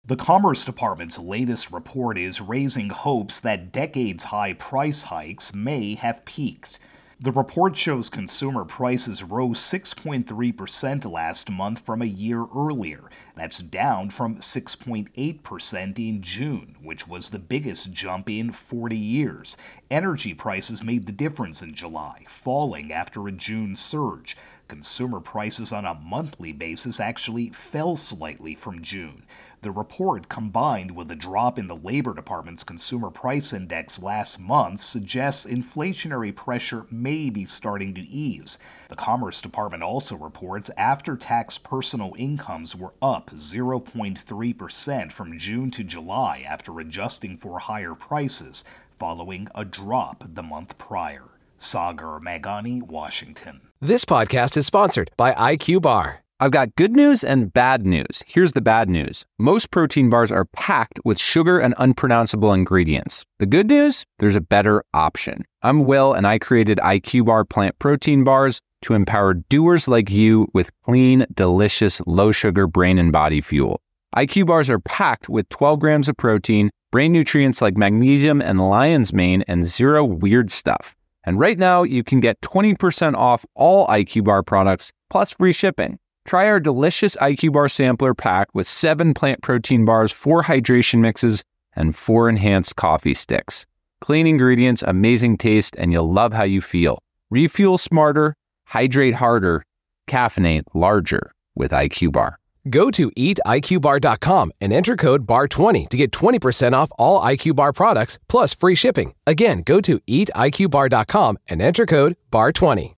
reports on Consumer Spending.